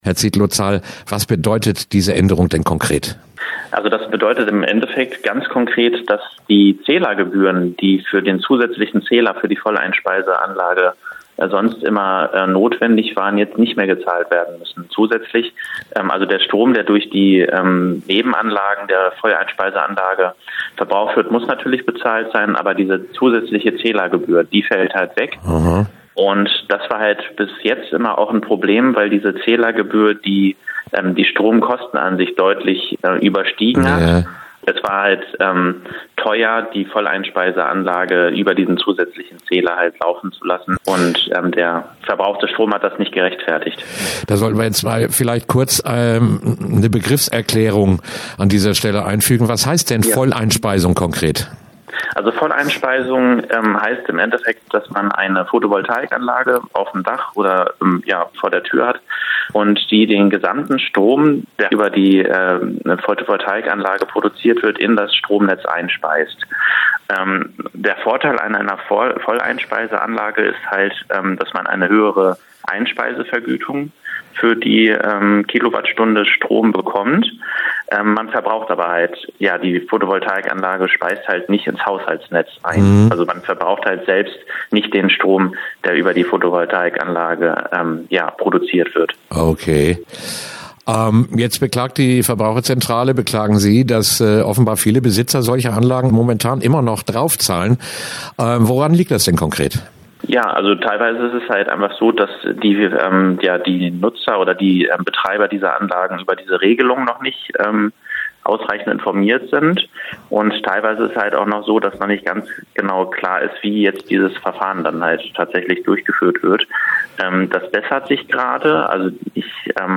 Verbraucherschutz im Gespräch: Worauf Besitzer von PV-Anlagen jetzt achten sollten - Okerwelle 104.6